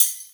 Tambourine Rnb 2.wav